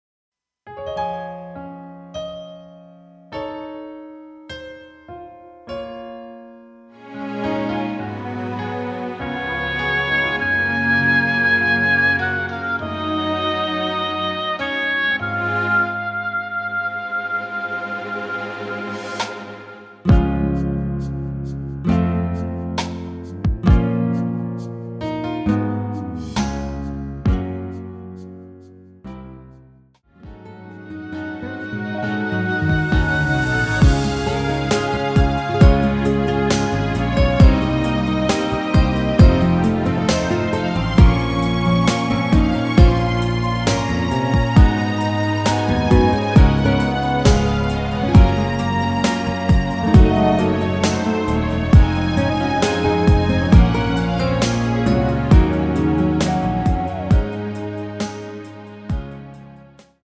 Db
◈ 곡명 옆 (-1)은 반음 내림, (+1)은 반음 올림 입니다.
앞부분30초, 뒷부분30초씩 편집해서 올려 드리고 있습니다.
중간에 음이 끈어지고 다시 나오는 이유는
위처럼 미리듣기를 만들어서 그렇습니다.